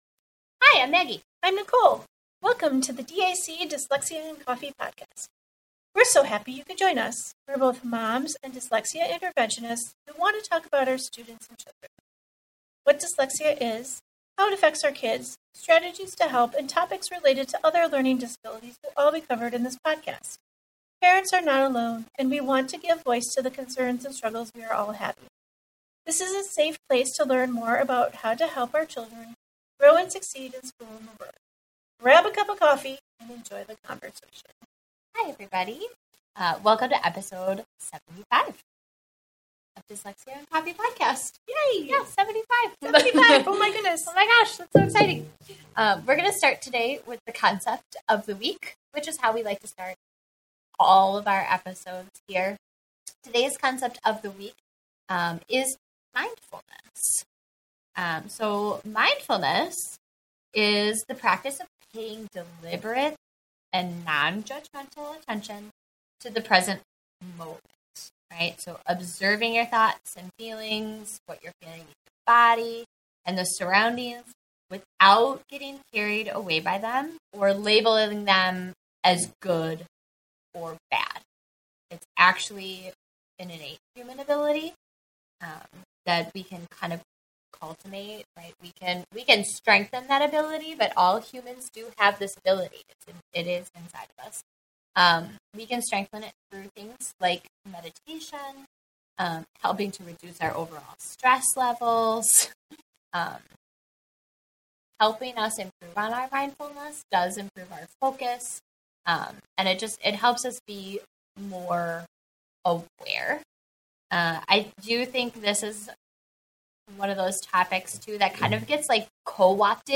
We are both moms and dyslexia interventionists who want to talk about our students and children.